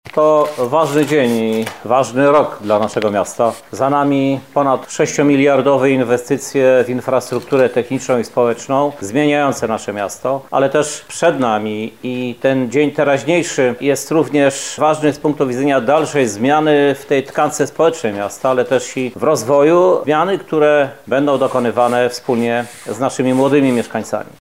-mówi prezydent Lublina, Krzysztof Żuk.